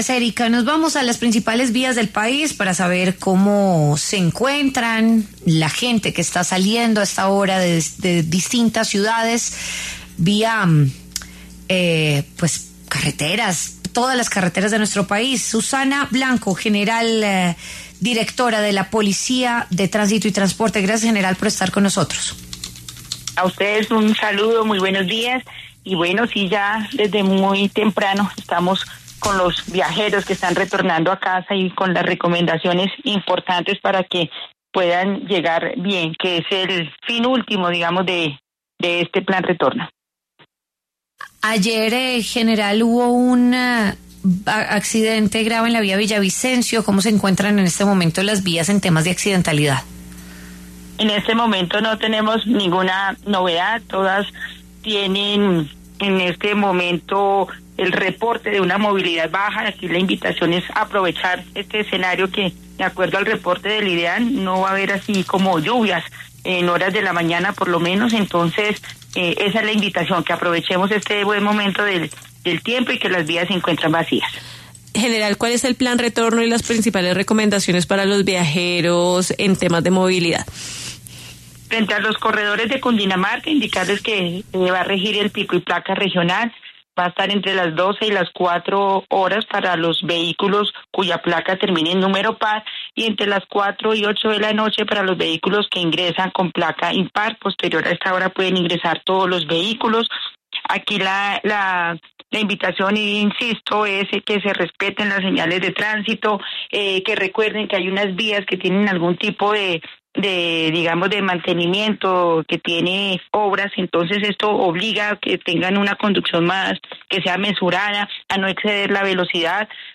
Coronel Susana Blanco, directora de Tránsito y Transporte de la Policía, habló en La W sobre el ‘plan retorno’ de este puente festivo del 24 de marzo.